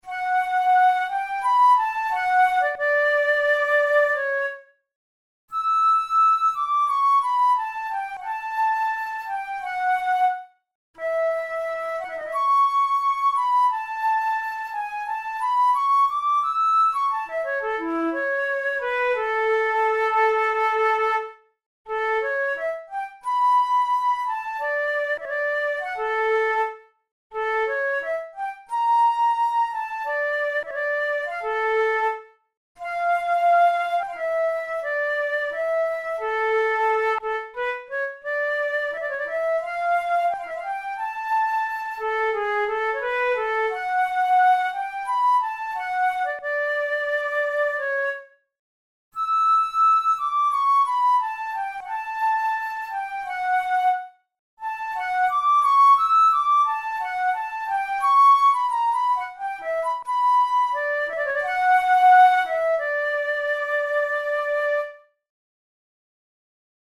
Etudes, Written for Flute